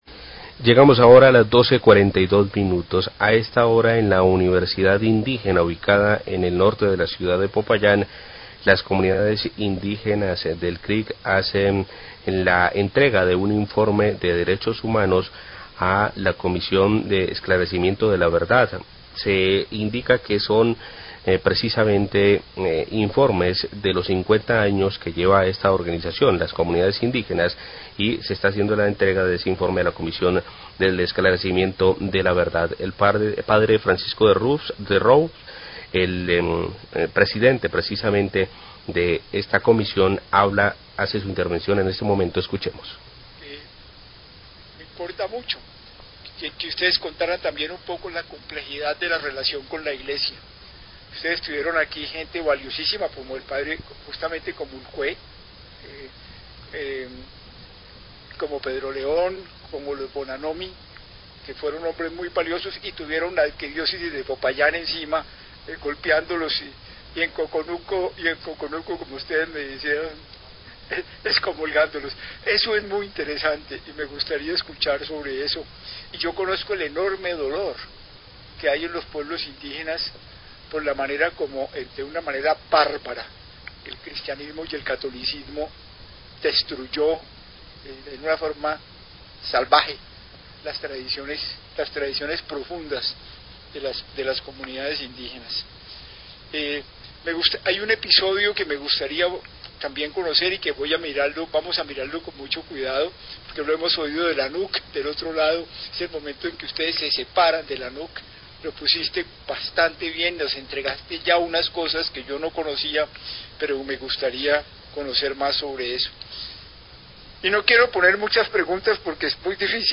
Radio
El Consejo Regional Indígena del Cauca-CRIC, entregó a la Comisión de la Verdad un informe de derechos humanos sobre las afectaciones a las comunidades indígenas durante el conflicto armado. Declaraciones del presidente de la Comisión, padre Francisco DeRoux, durante la reunión en la Universidad Indígena.